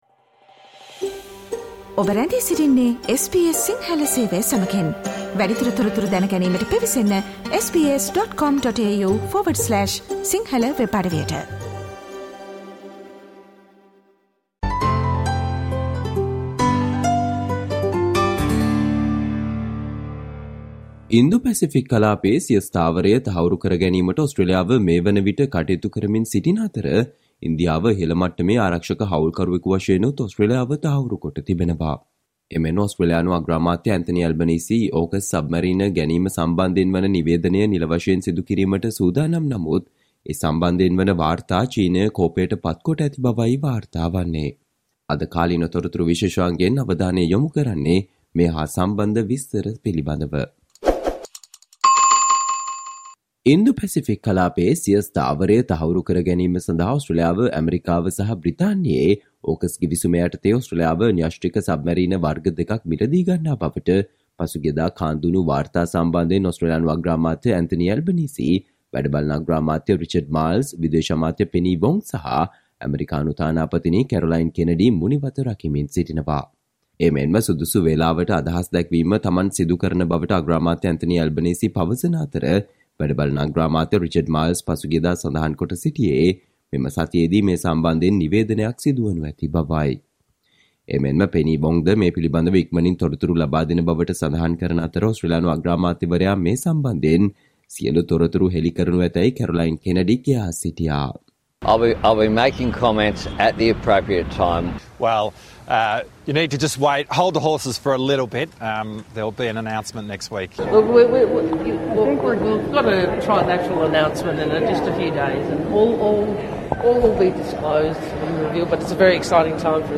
Today - 13 March, SBS Sinhala Radio current Affair Feature on AUKUS deal